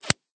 zip_snap.ogg